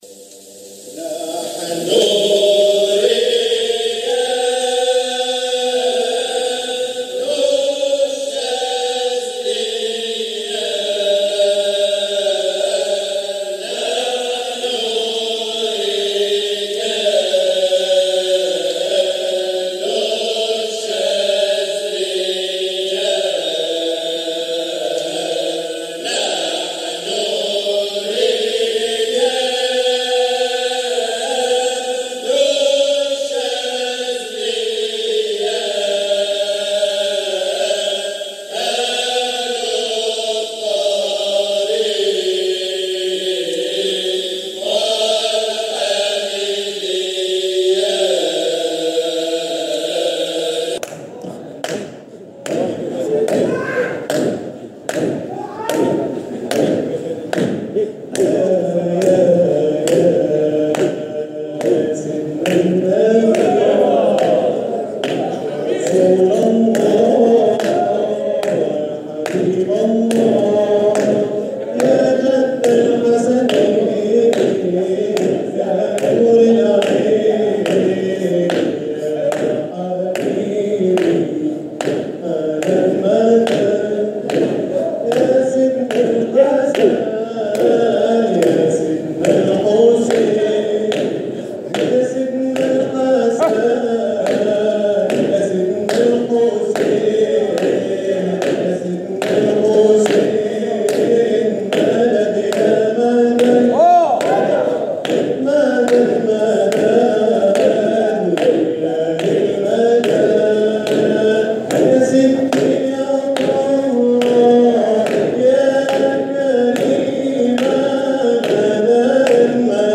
جزء من حلقة ذكر بمسجد سيدتنا السيدة سكينة 11